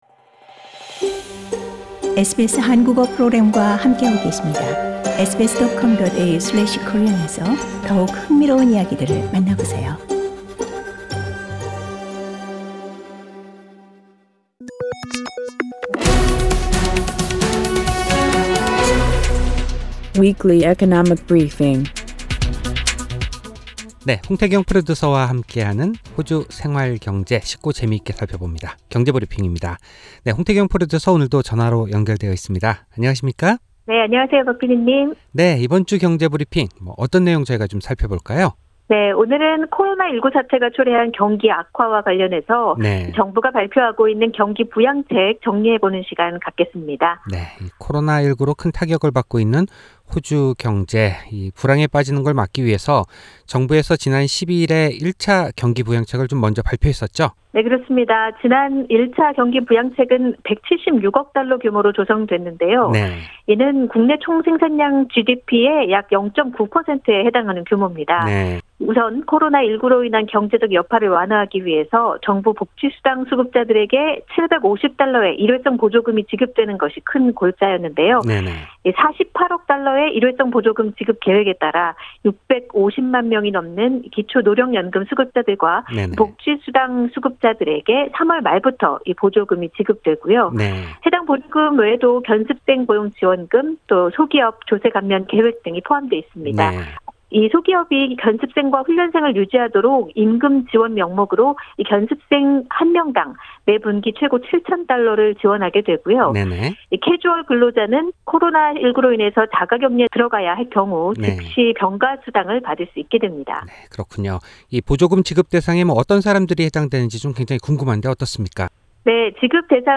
전화 연결돼 있습니다.